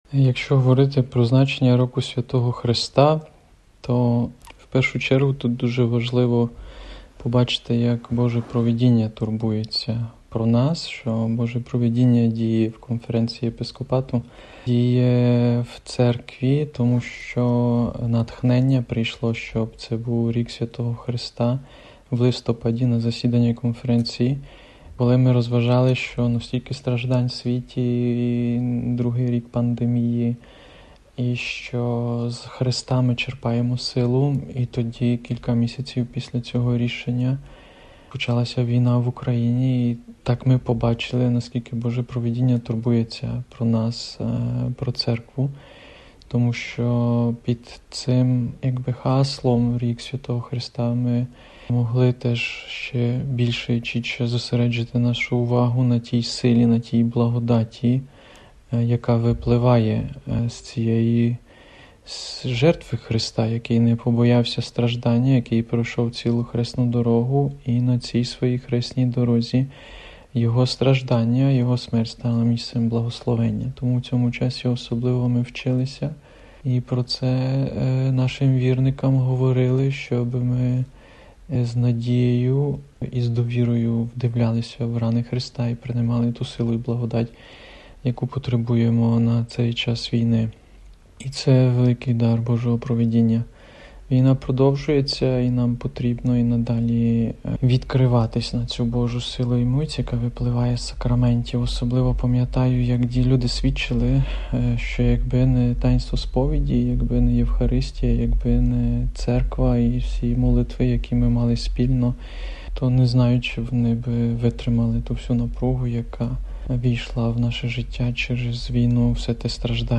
Своїми думками з приводу цього в інтерв’ю для Радіо Ватикану – Vatican News поділився єпископ Микола Лучок, Апостольський Адміністратор Мукачівської дієцезії.